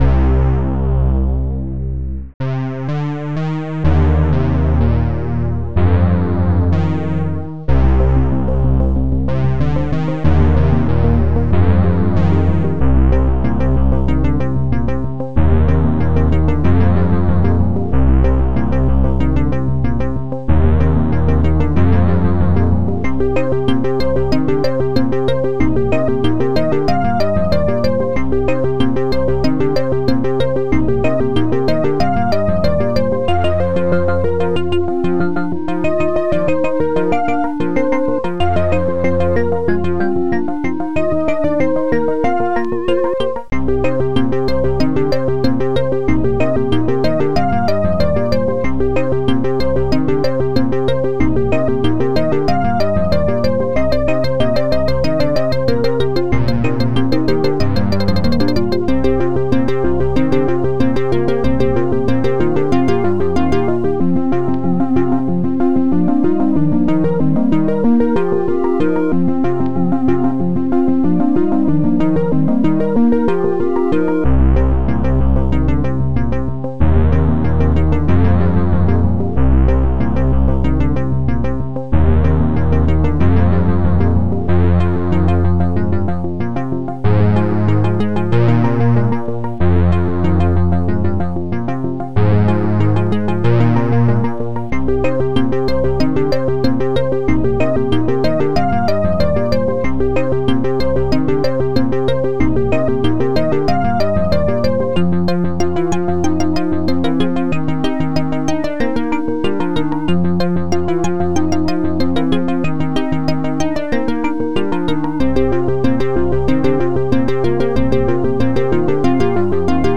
SoundTracker Module